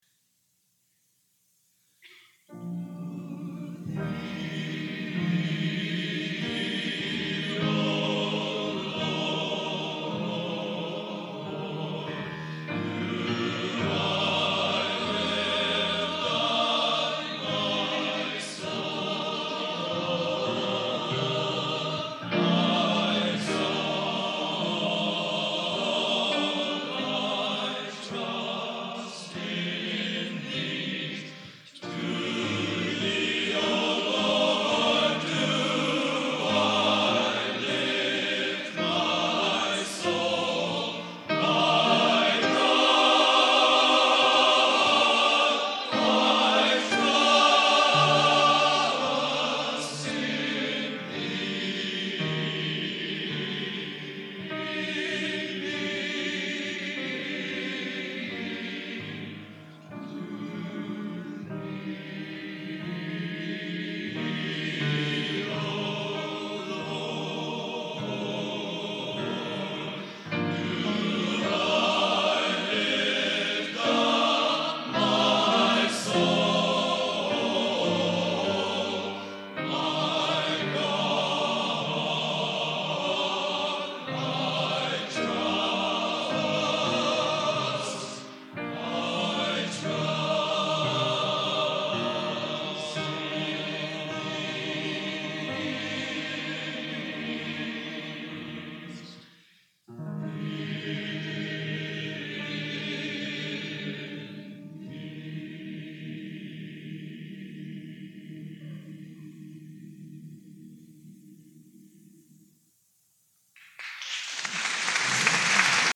Genre: Classical Modern Sacred | Type: